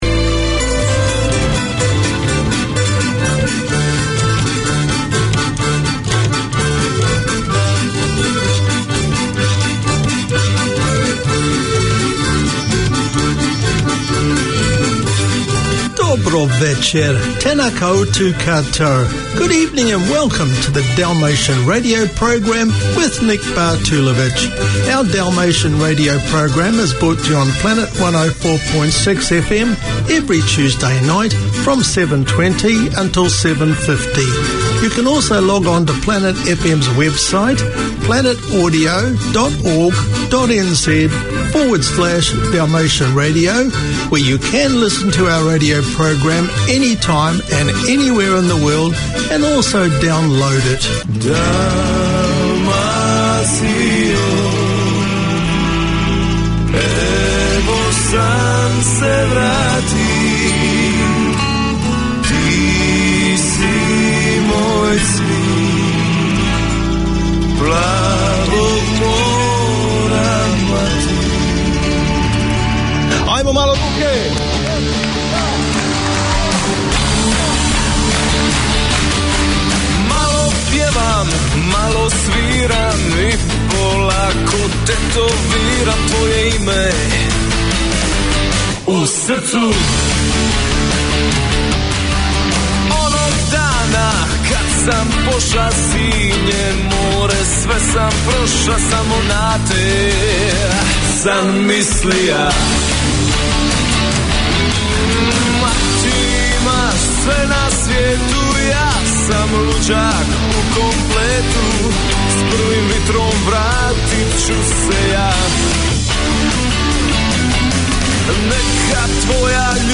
We present Society news and explore the achievements of Kiwis of Dalmatian descent. The music selected from around the former Yugoslavia is both nostalgic and modern.